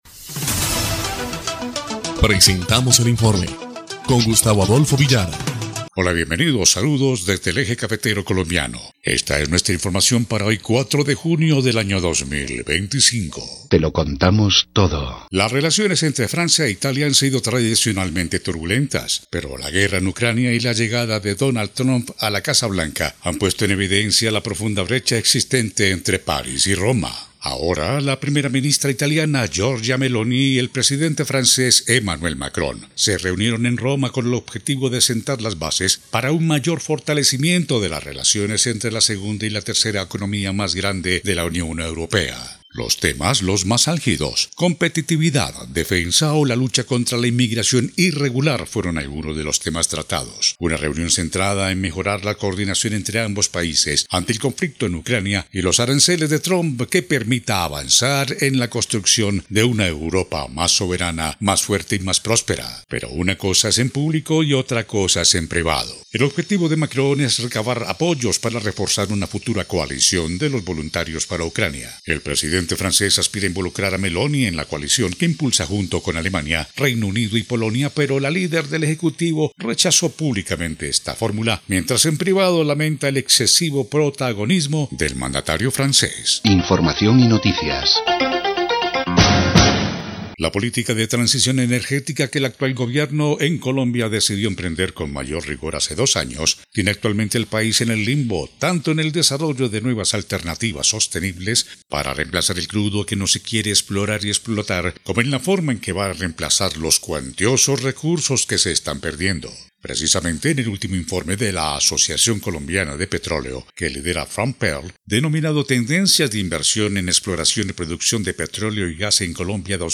EL INFORME 2° Clip de Noticias del 4 de junio de 2025